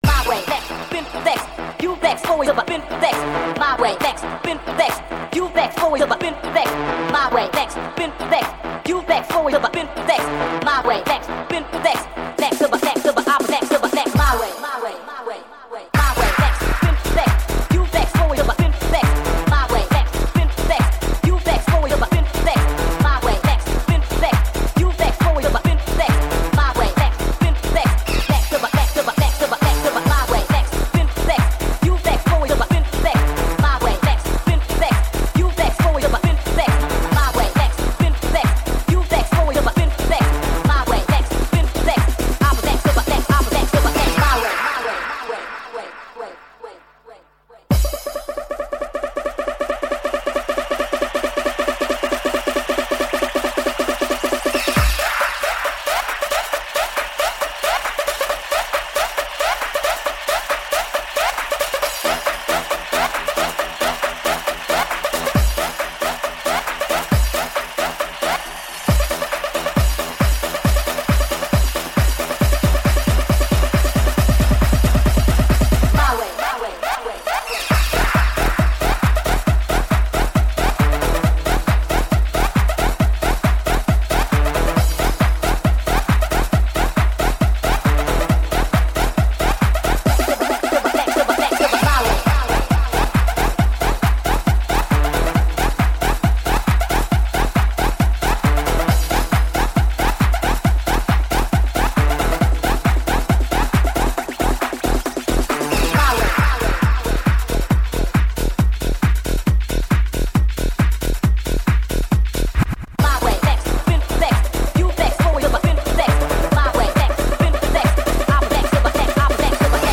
Жанр: Club-House